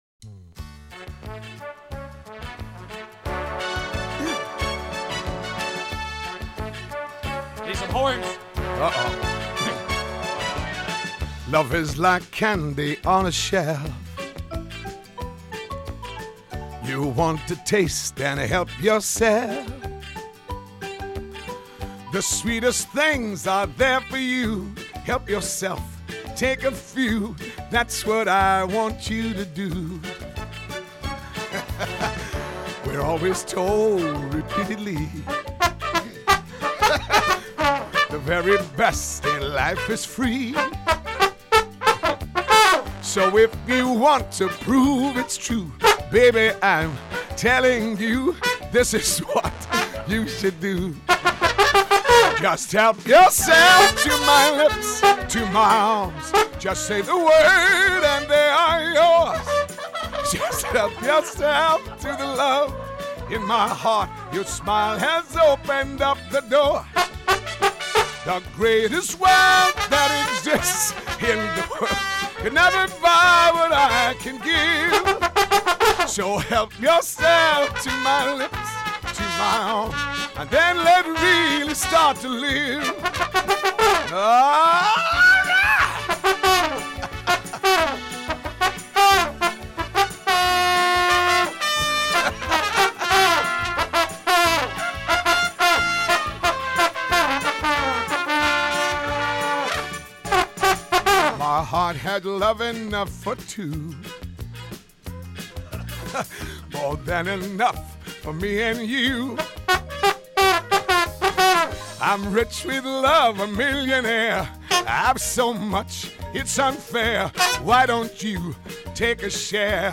When Tom Jones guested on Mark & Brian’s syndicated Los Angeles morning radio show, he’d apparently agreed to sing his hit “Help Yourself” without realizing he’d receive “accompaniment”.
Mark & Brian’s horn section adds something special to the mix, cracks Jones up, and even throws off his performance toward the end, to the delight of his hosts.